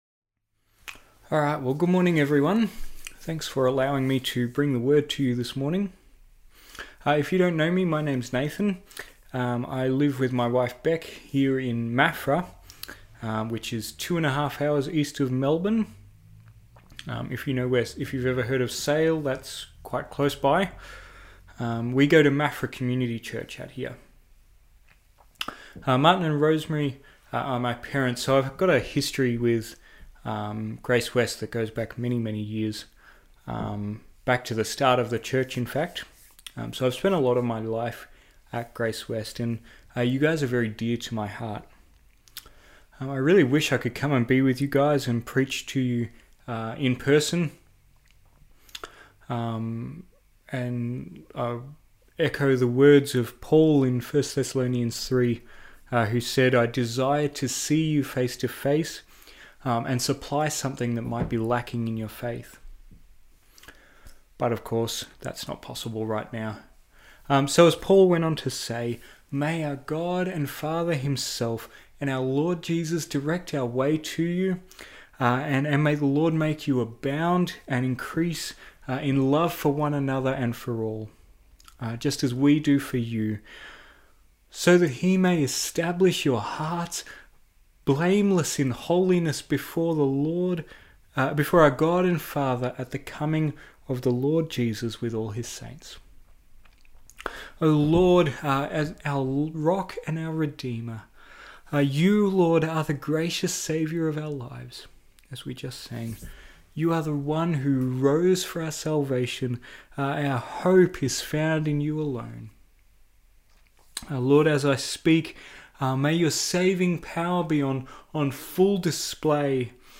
Topical Sermon Passage: 2 Kings 5:1-27 Service Type: Sunday Morning